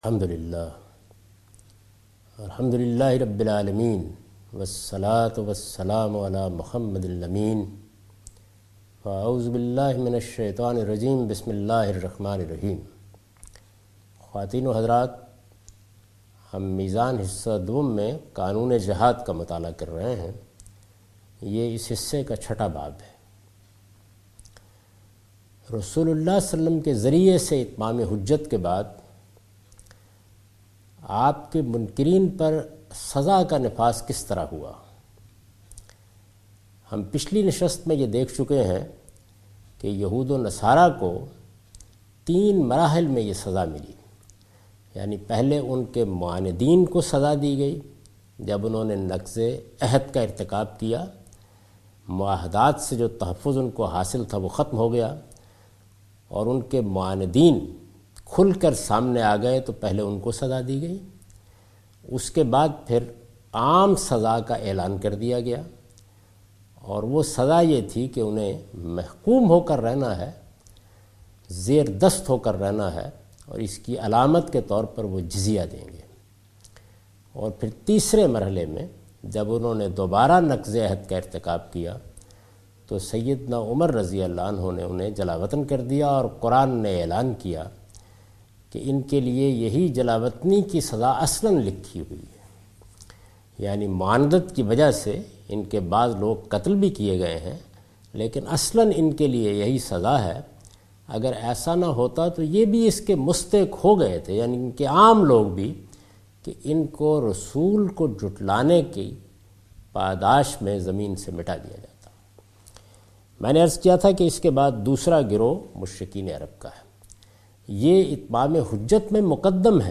A comprehensive course taught by Javed Ahmed Ghamidi on his book Meezan.
In this sitting ultimate goal of Jihad is explained from Quran. Itmam e Hujjah on Arabs and on other nations through letters by Prophet (P B U H) is also discussed in this lecture.